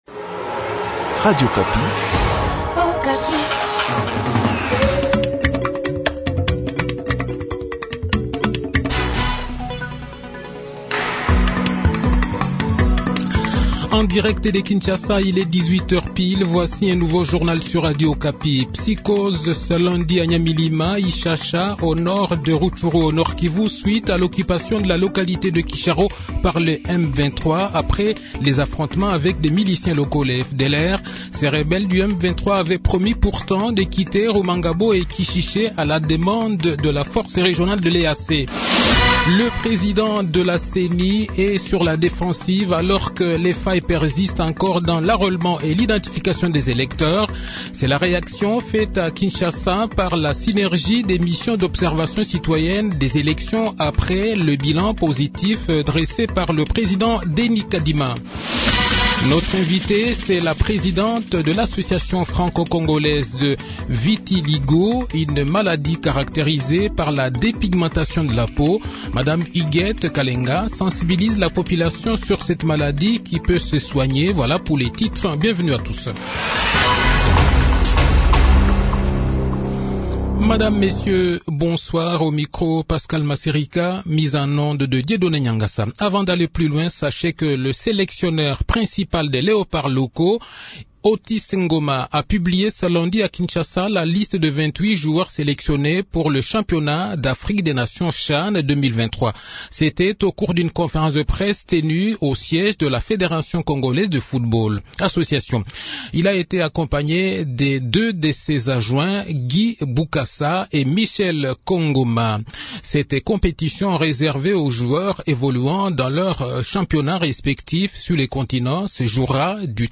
Le journal de 18 h, 2 janvier 2023